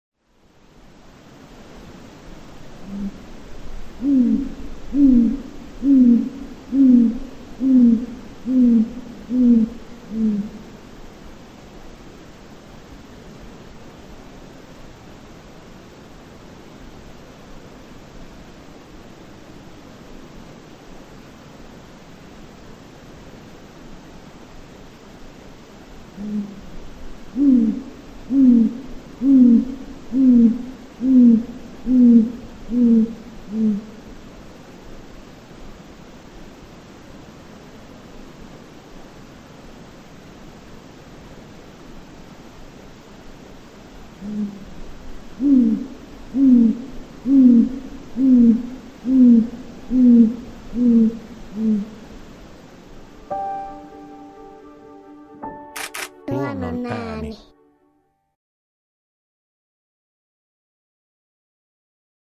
Kuuntele: Lapinpöllö
Lapinpöllö on huuhkajan jälkeen suurin pöllömme, jopa 70 senttiä pitkä. Lapinpöllön soidinääni on vaimeneva, hyvin kumea puuskutus ”huh-huh-huh…”, tavallisesti 8–12-tavuinen. Kuuntele lapinpöllön ääninäyte!